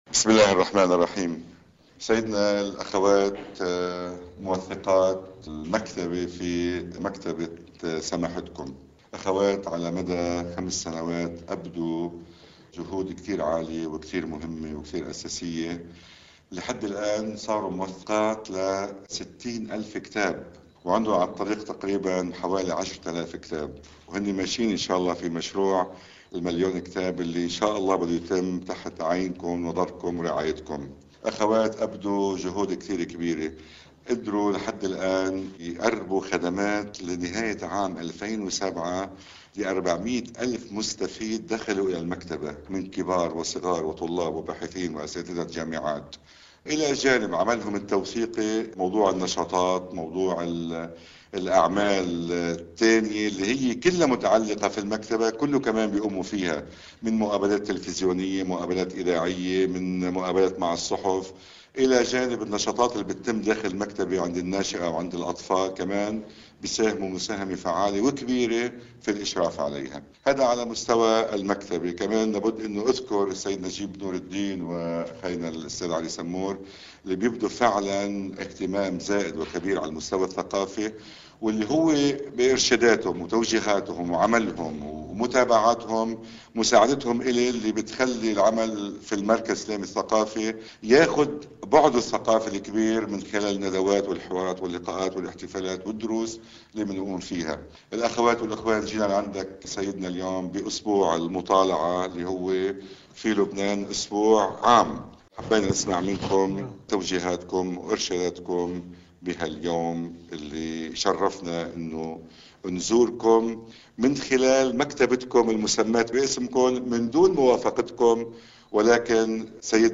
- كلمة للعلامة المرجع السيد محمد حسين فضل الله(رض) أمام وفد مسؤولي مكتبة السيد العامة وذلك بمناسبة يوم القراءة والمطالعة، تحدث فيها سماحته عن دور القراءة والمطالعة في صناعة الثقافة للإنسان، مؤكداً أن القرآن الكريم يخطط في المنهج الإسلامي لصنع العقل وتنميته وتطويره ومنحه الحريّة..